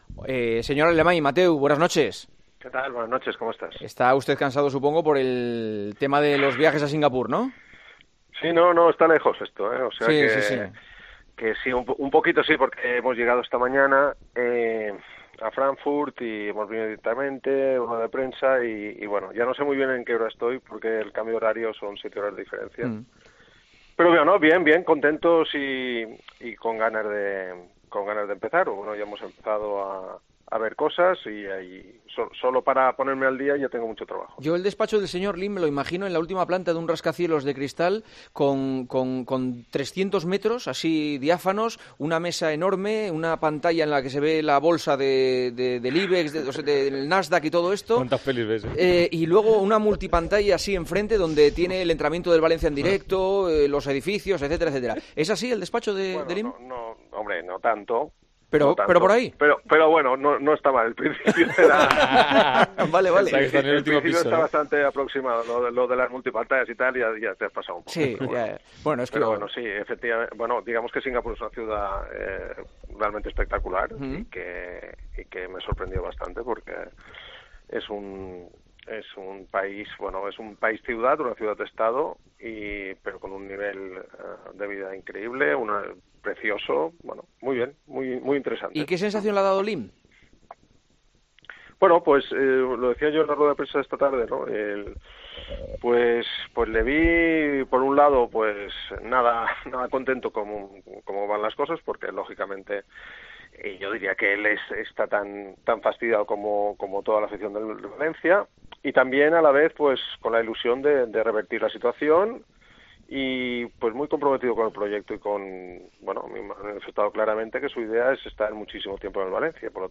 Entrevista en El Partidazo de COPE